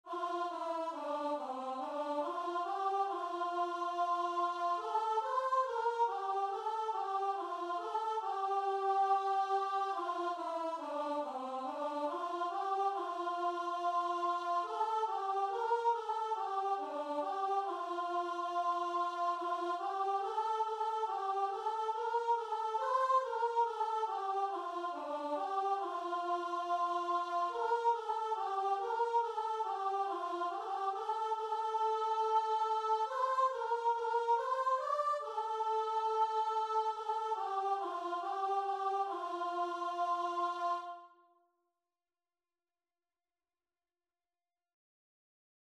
4/4 (View more 4/4 Music)
Choir  (View more Easy Choir Music)
Christian (View more Christian Choir Music)